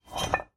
Звуки гантелей
Звук поднятия гантели с тренажера